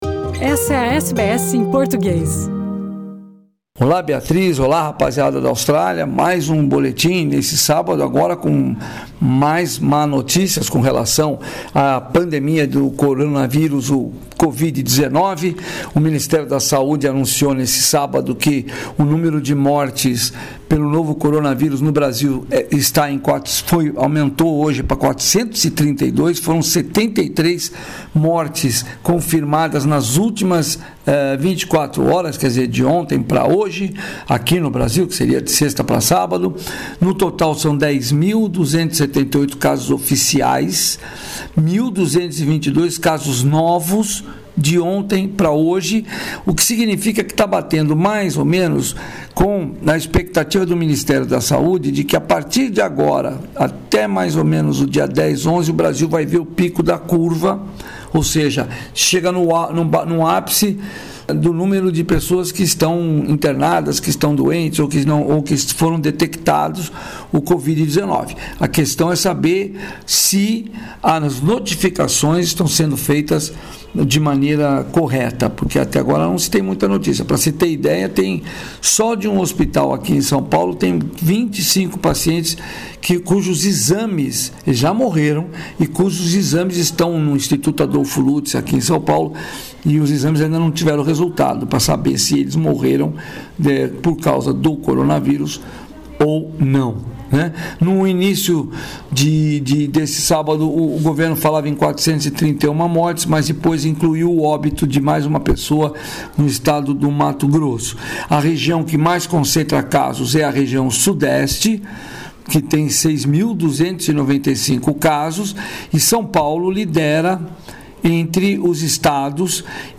Boletim